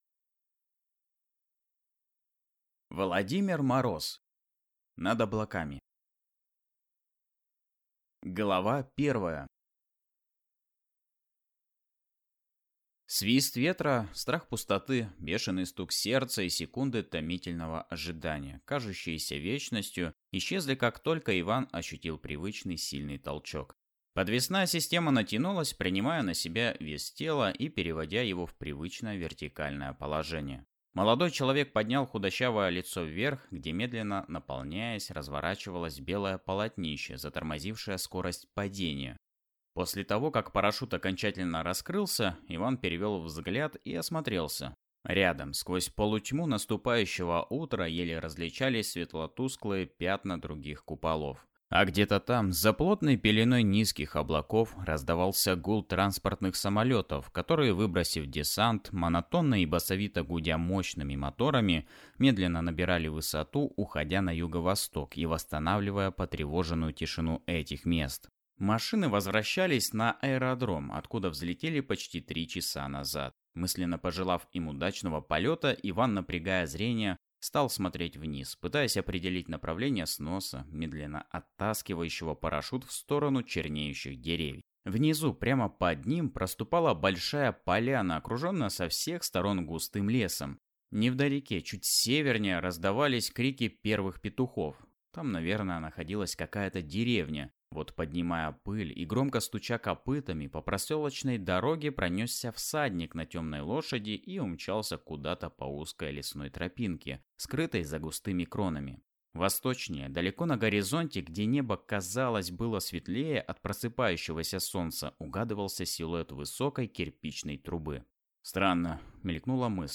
Аудиокнига Над облаками | Библиотека аудиокниг